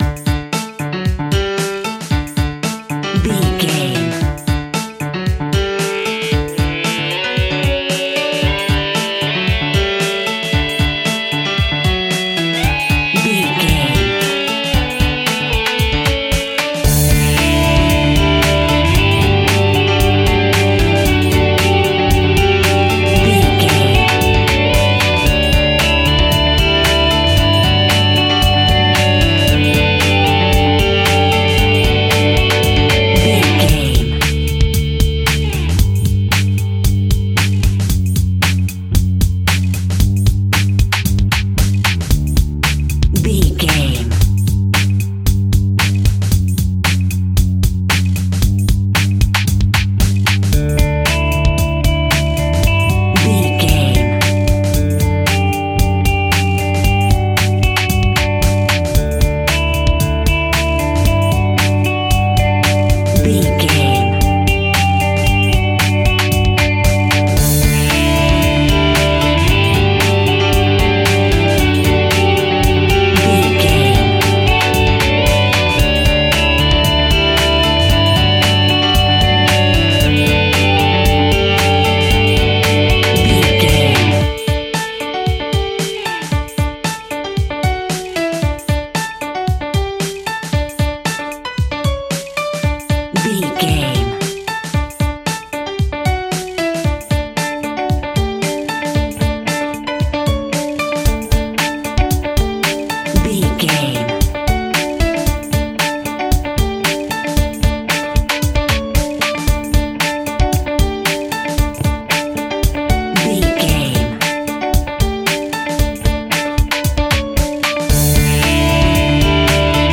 Ionian/Major
D
happy
hopeful
joyful
lively
bright
electric guitar
bass guitar
drums
piano